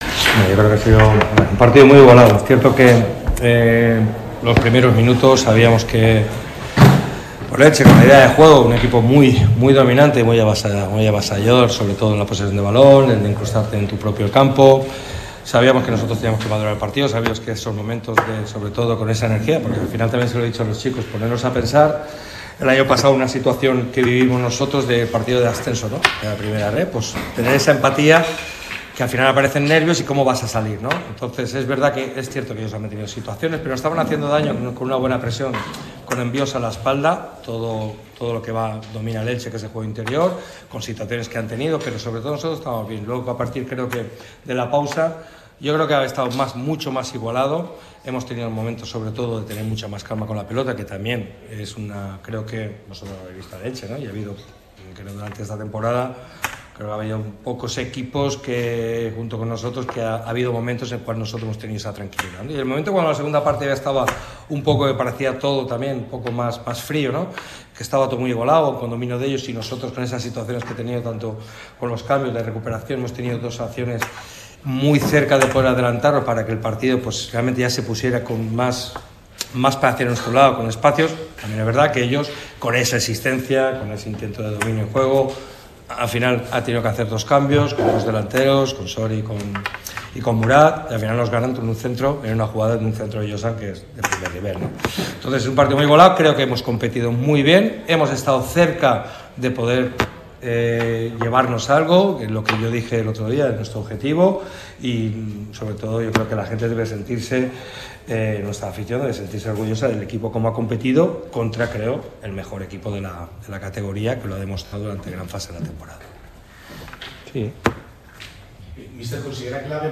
Pellicer compareció ante los medios y mando un mensaje a los malaguista.